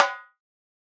Rimshot Zion 2.wav